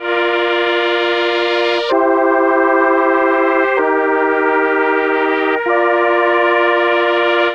Track 10 - Synth 01.wav